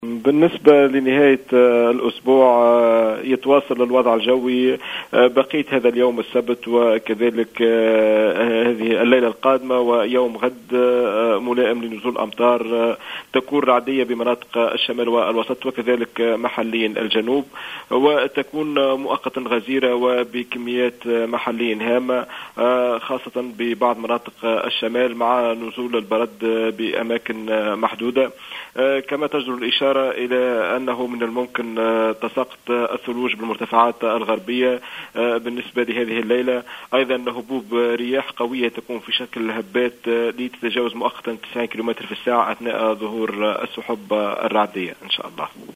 في تصريح هاتفي للجوهرة أف أم عن التوقعات الجوية لبقية هذا اليوم والأيام القادمة.